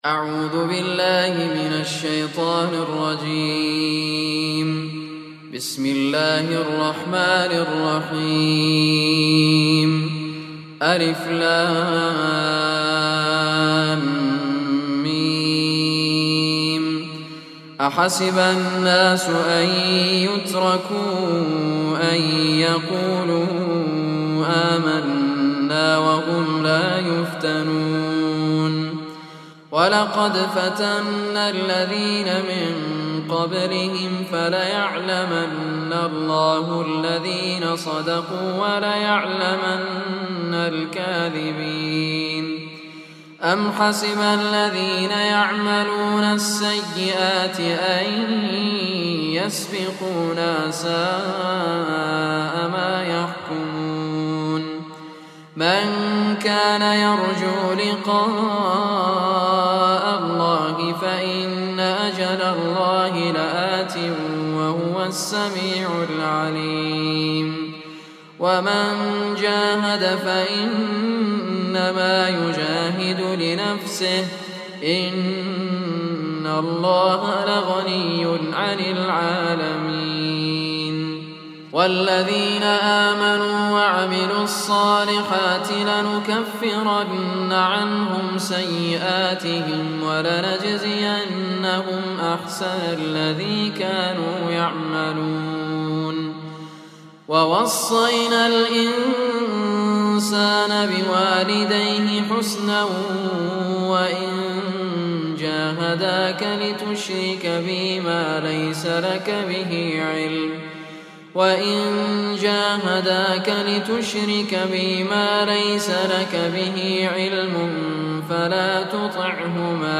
Surah Al-Ankabut Recitation